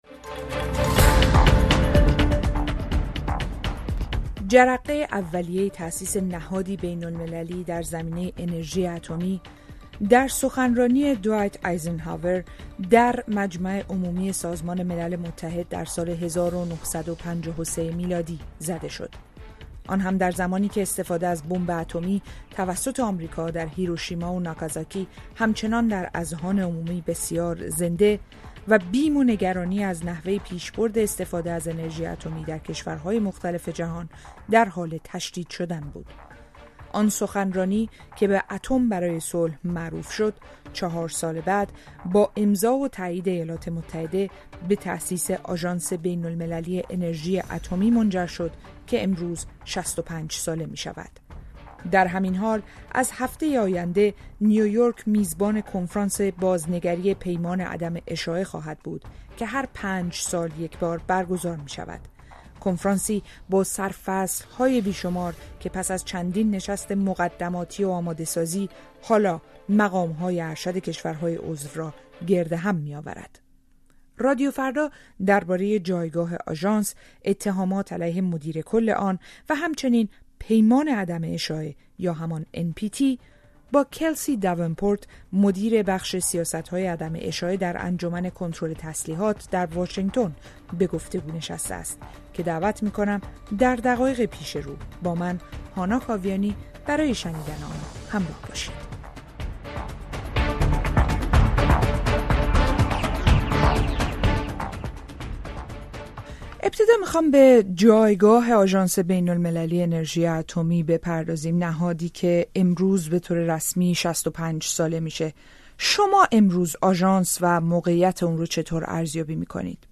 ۶۵ سالگی آژانس؛ گفت‌وگو